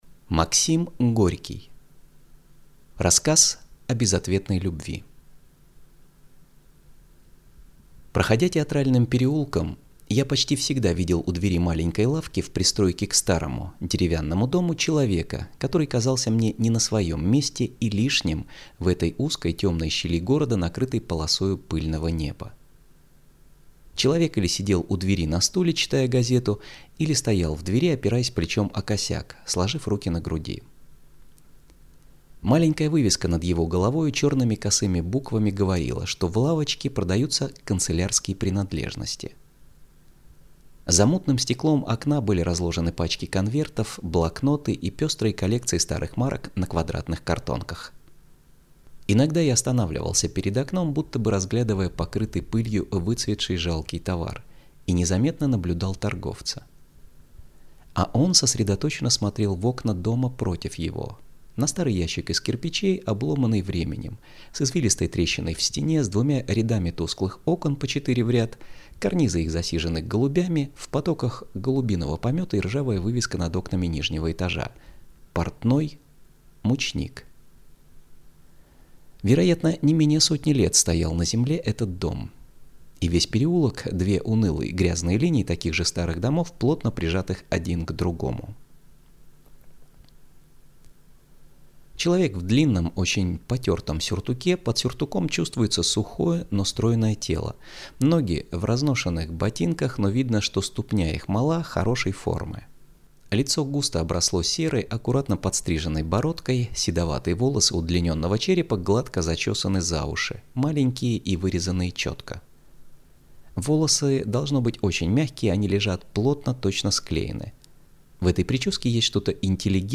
Аудиокнига Рассказ о безответной любви | Библиотека аудиокниг